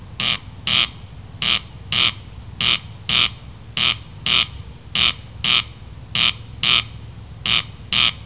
Коростель (Crex crex)
Crex-crex.wav